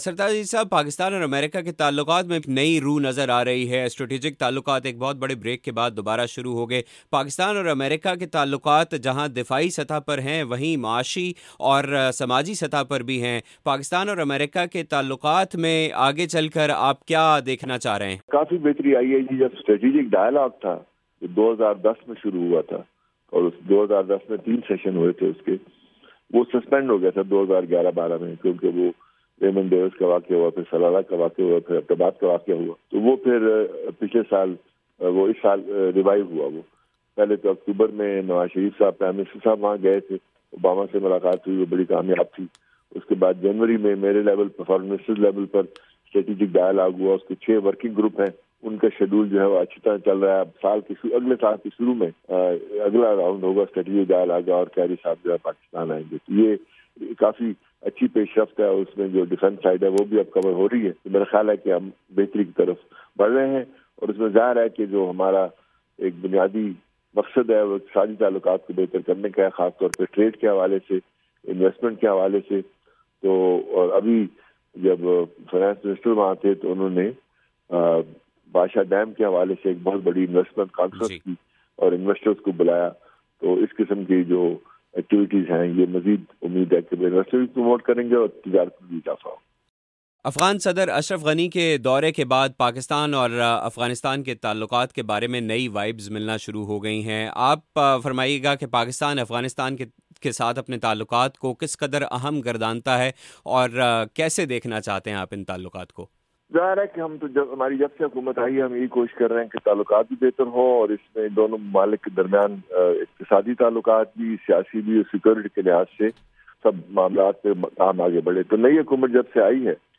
سرتاج عزیز کی وائس آف امریکہ سے خصوصی بات چیت